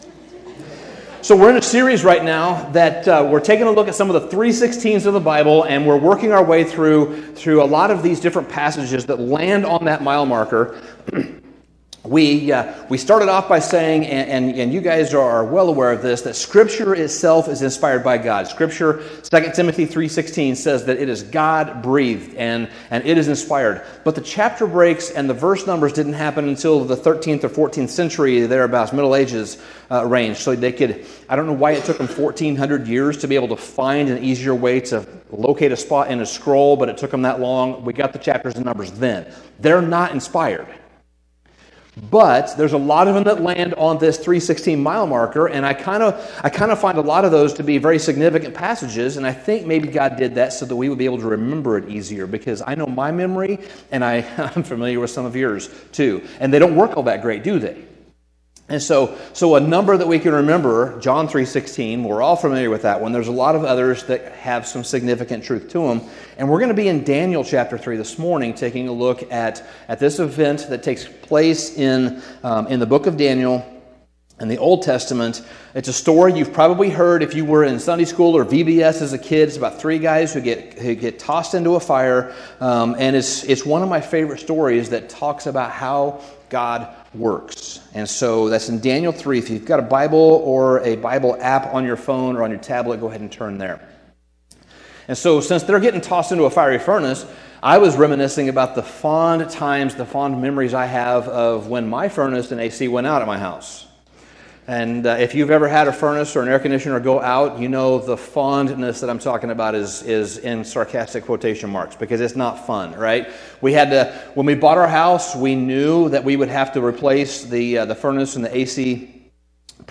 Sermon Summary Daniel 3 contains the interesting account of three Hebrew men who were cast into a fiery furnace for standing up against the idolatrous practice of a pagan king.